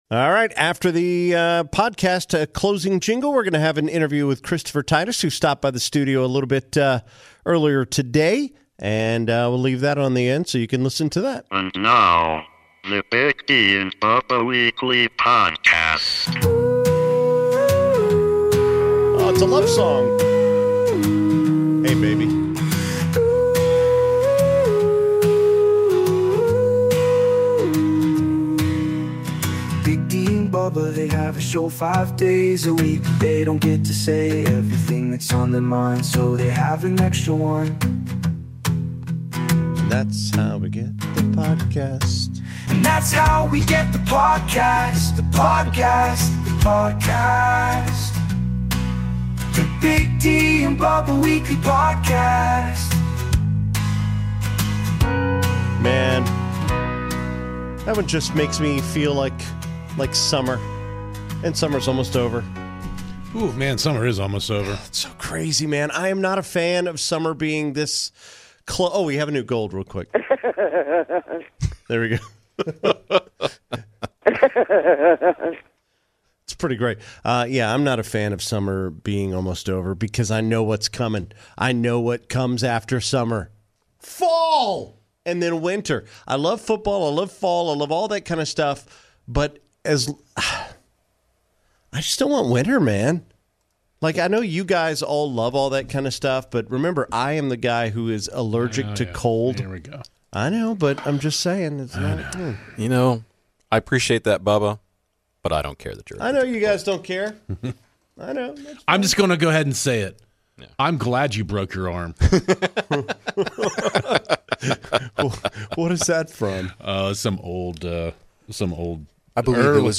Plus, comedian Christopher Titus stops by to share some of his latest material!